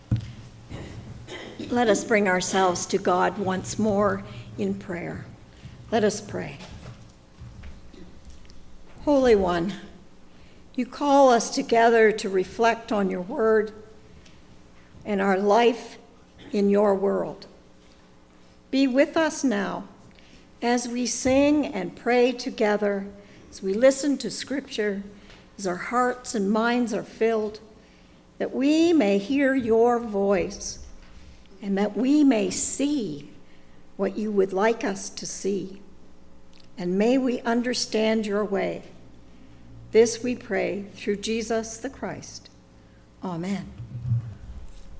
This is a shortened version of our in-person  Sunday Service.
Prayers of the People and the Lord’s Prayer